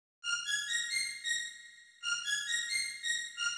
GAR String Riff F-Ab-Bb-C.wav